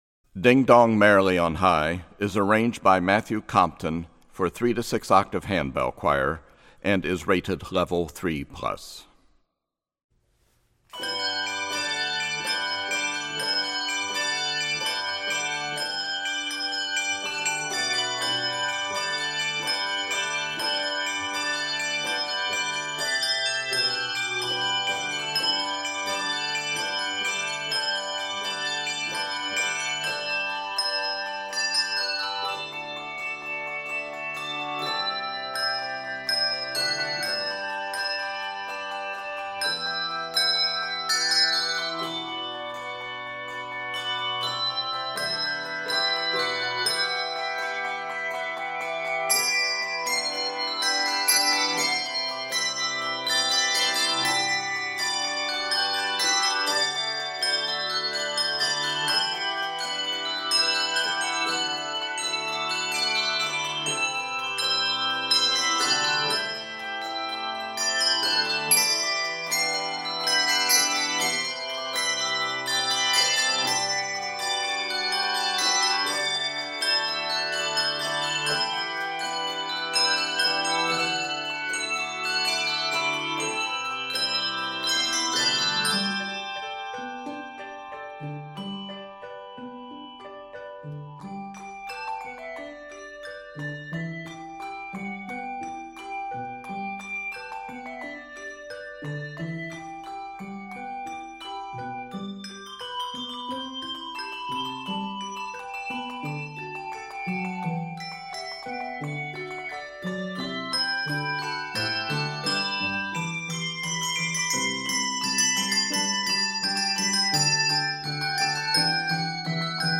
Key of G Major.
Octaves: 3-6